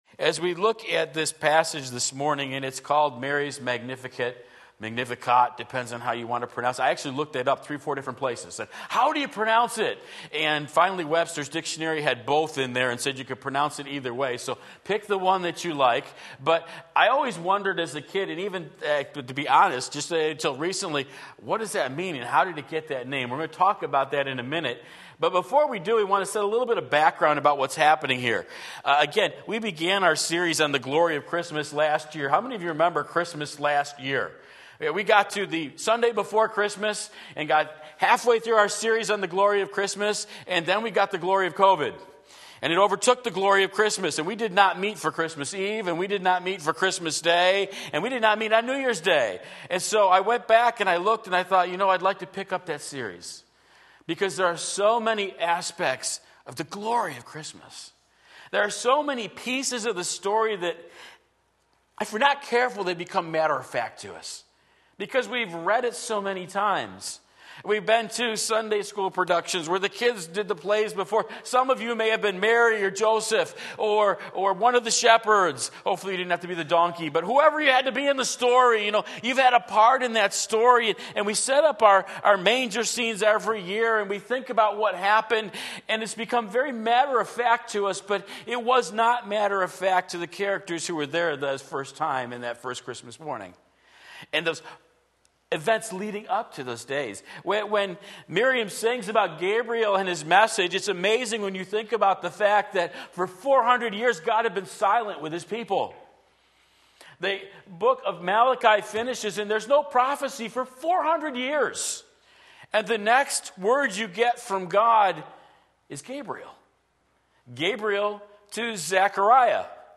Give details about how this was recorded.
The Glory of the God of Christmas Luke 1:46-55 Sunday Morning Service